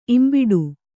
♪ imbiḍu